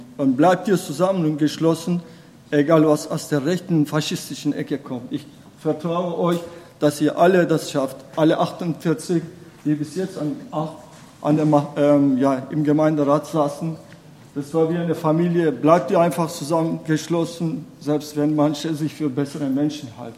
Begrüßung der neuen Gemeinderät*innen: Freiburger*innen demonstrieren vor dem Rathaus und im Rathaus gegen neue AfD-Stadträte